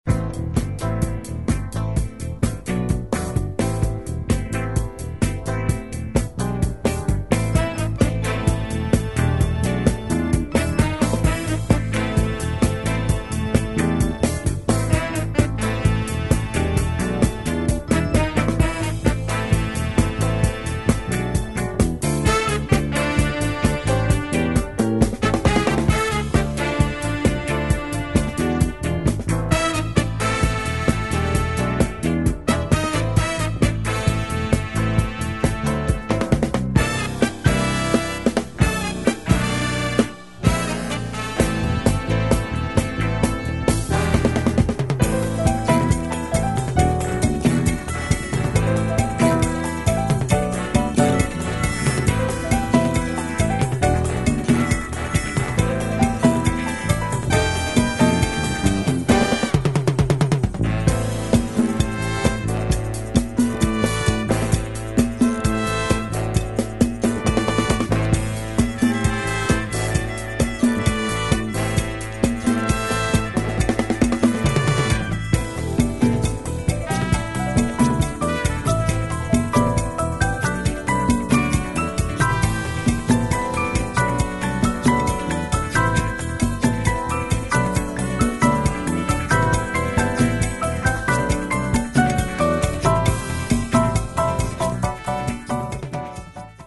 Killer Italian disco B-side